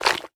slime10.wav